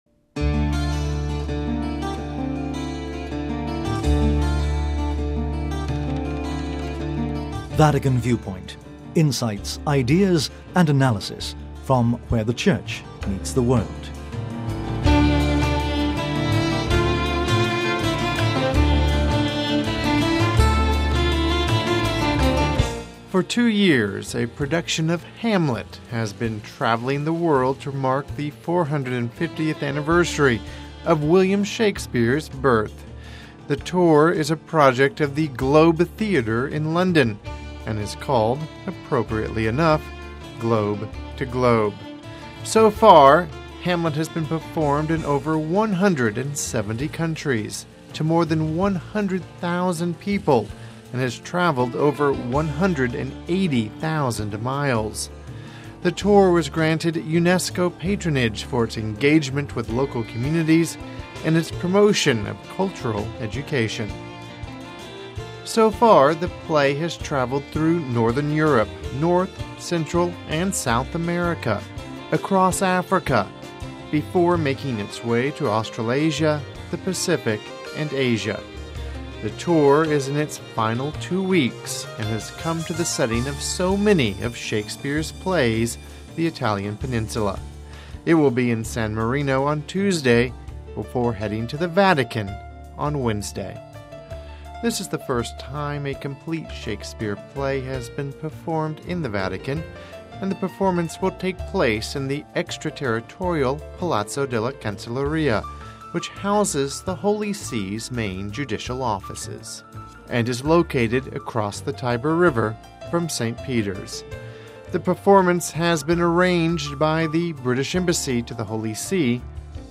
Listen to the Vatican Viewpoint featuring the interview with Bishop Paul Tighe: